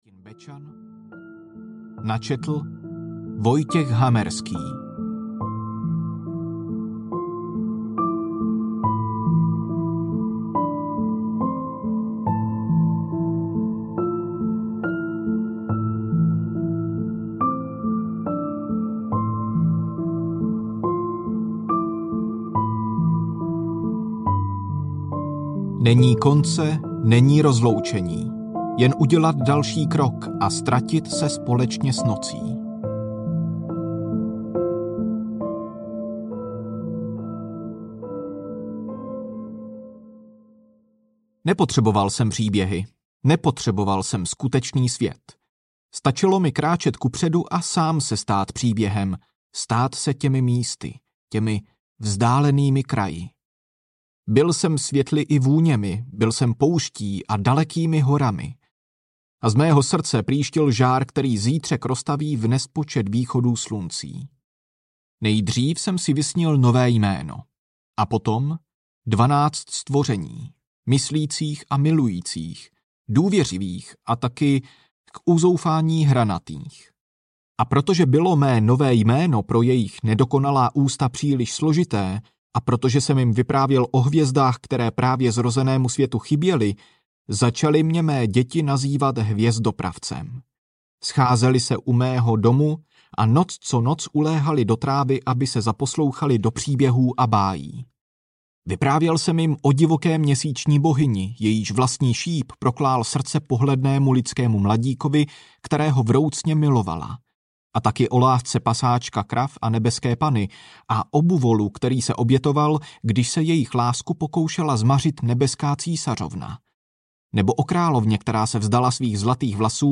Hvězdopravec audiokniha
Ukázka z knihy